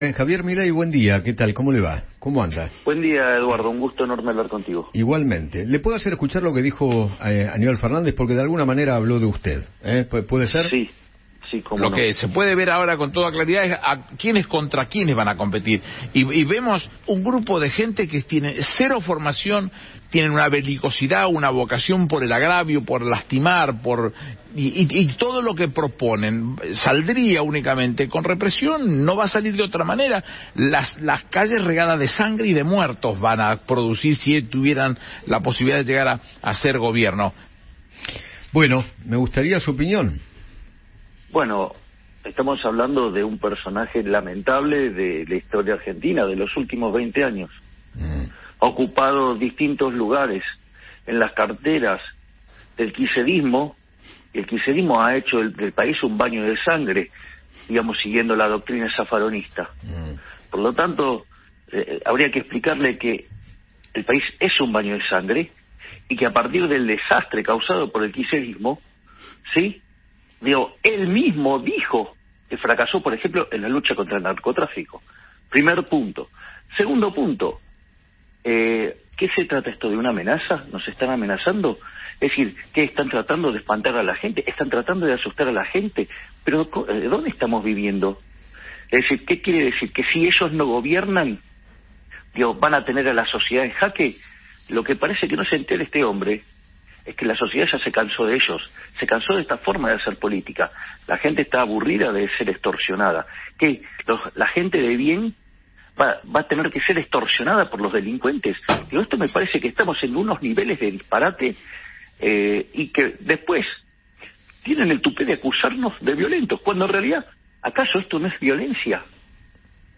Javier Milei, diputado nacional por la Libertad Avanza, conversó con Eduardo Feinmann sobre los dichos del ministro de Seguridad bonaerense, quien aseguró que “las calles van a estar regadas de sangre y muertos” si la oposición gana las elecciones.